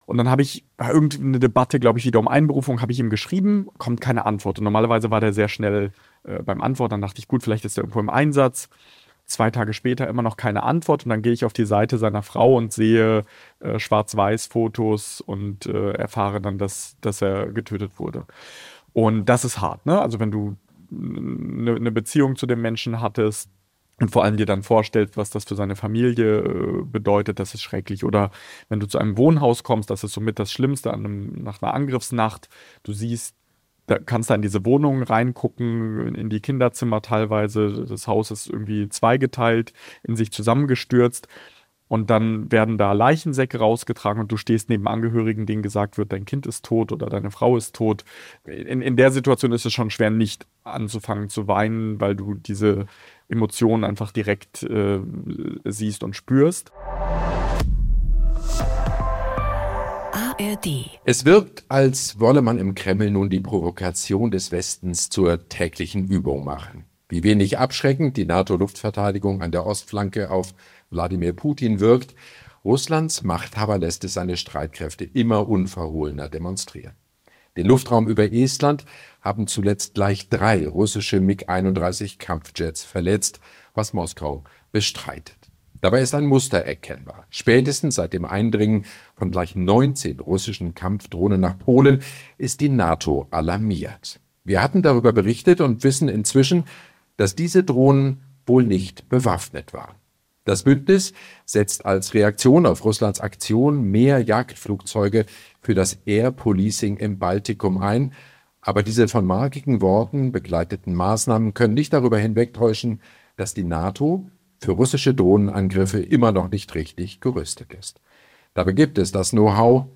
Im Saal ist es plötzlich sehr leise und Golod berichtet dann von einer Begegnung mit einem Familienvater, der sich für den Militärdienst gemeldet habe.